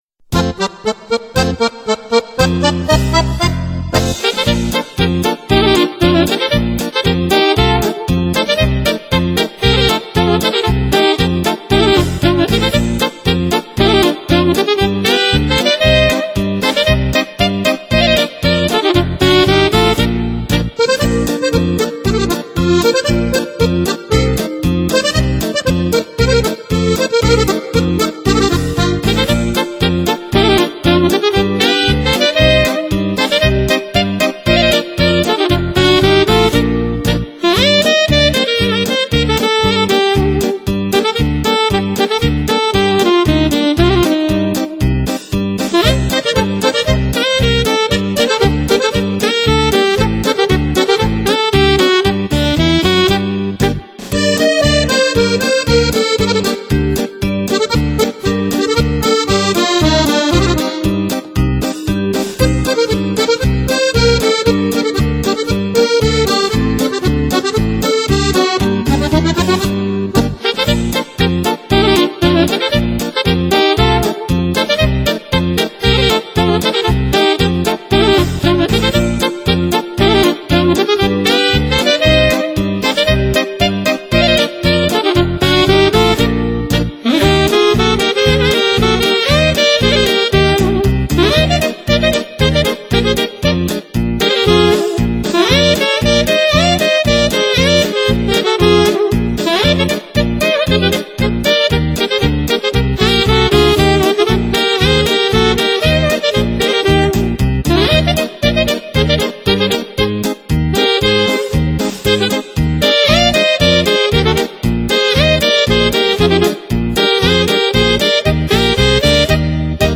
Valzer Mazurke Polke